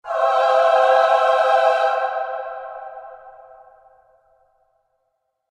Здесь собраны необычные звуковые композиции: от тонких природных мотивов до абстрактных эффектов, способных вызвать яркие ассоциации.
Звук просветления внутри